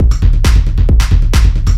DS 135-BPM B6.wav